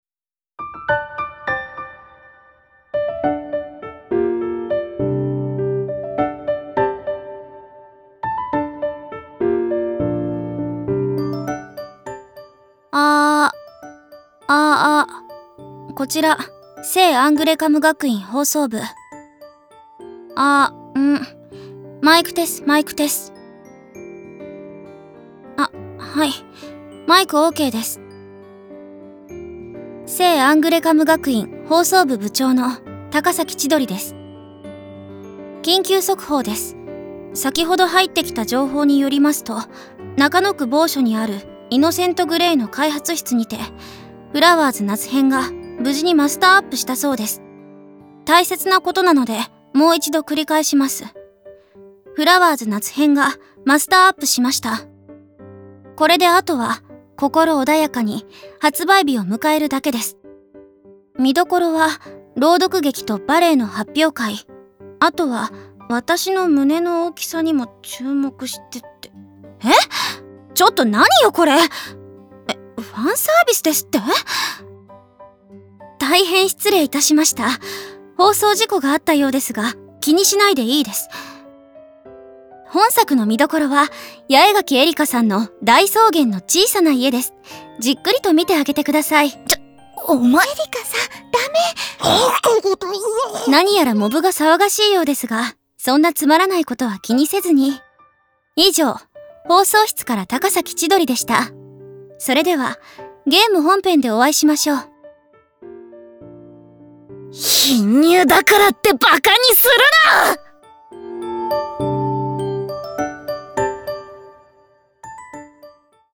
考崎 千鳥 CV 스자키 아야
마스터 업(최종완성) 스페셜 보이스